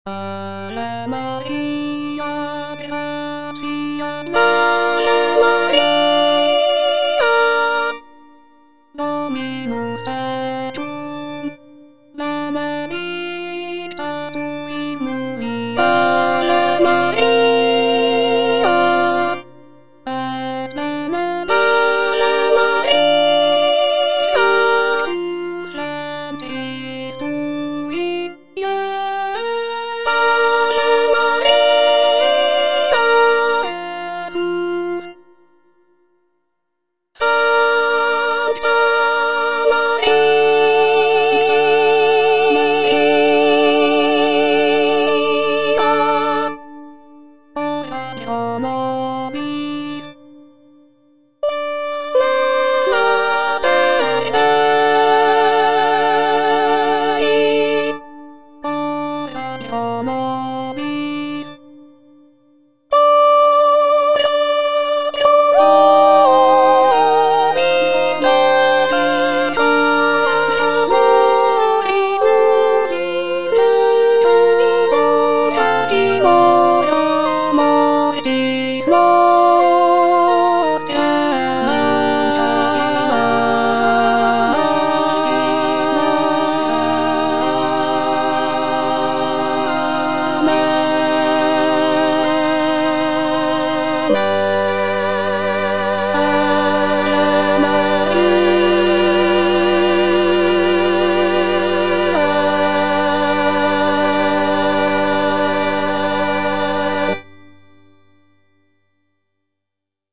Mezzosoprano (Emphasised voice and other voices) Ads stop
a choral work
Written in a lush and lyrical style